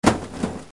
Suono meccanico
Suono metallico di macchina meccanica.